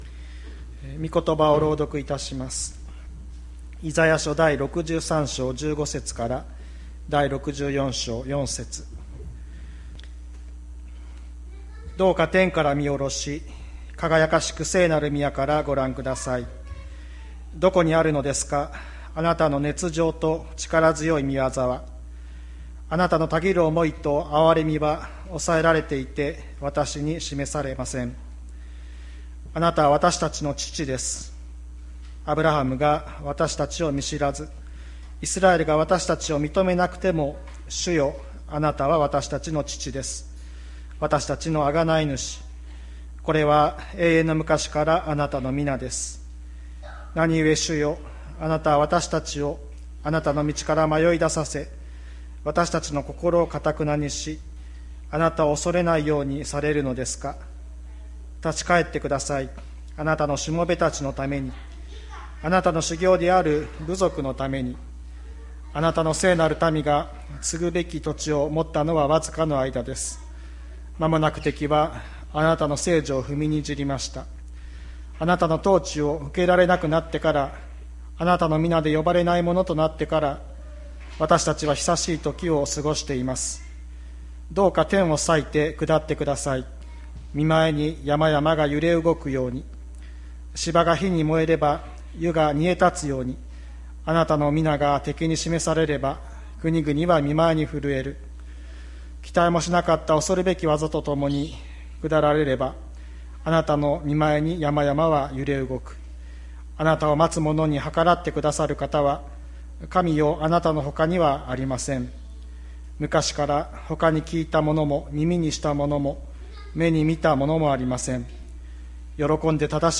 2021年08月22日朝の礼拝「神こそがあなたがたの父」吹田市千里山のキリスト教会